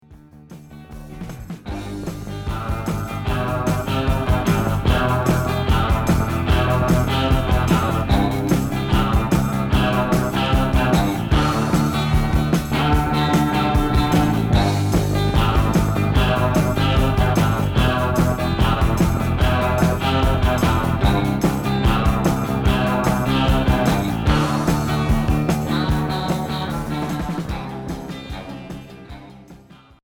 instrumental
Rock